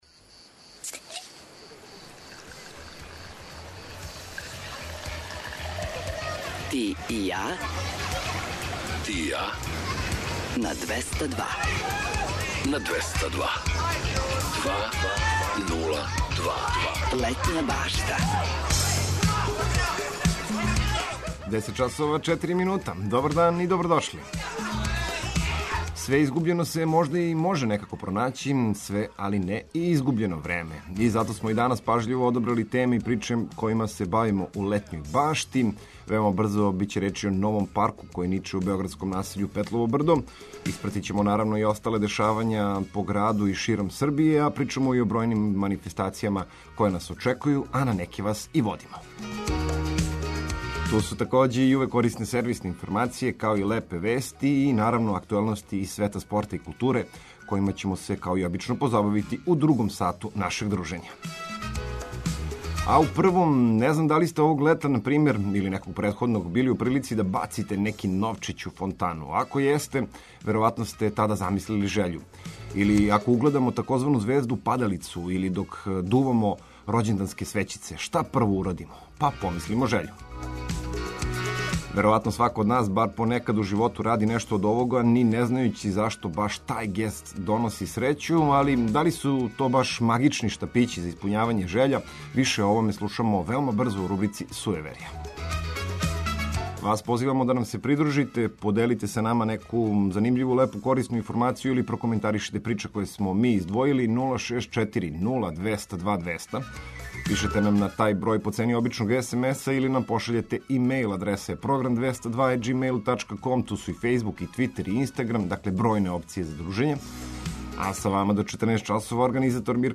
У преподневном делу Летње баште говоримо и о новом парку који ниче у београдском насељу Петлово брдо, биће речи и о бројним манифестацијама које нас очекују широм Србије, а на неке ћемо Вас и повести! Поред информација важних за организовање дана, ту су и лепе вести, приче из спорта и културе, као и ведра екипа и омиљена музика.